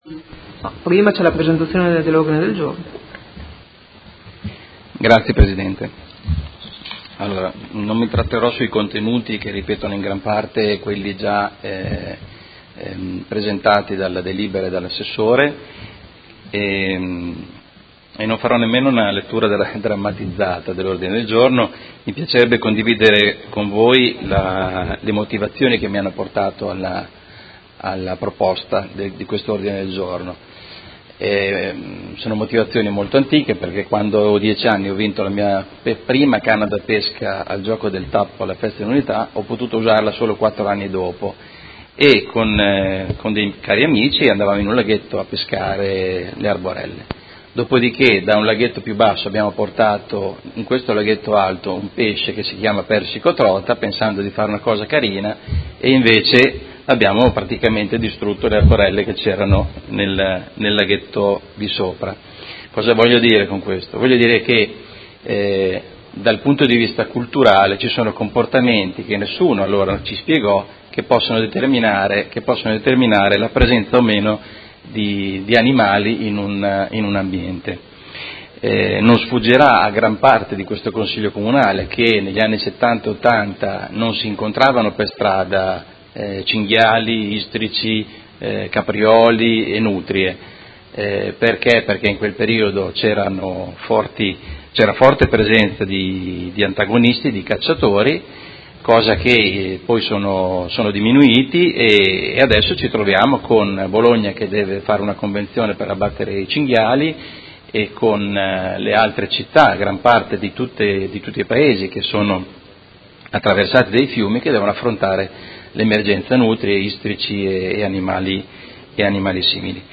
Seduta del 12 aprile 2018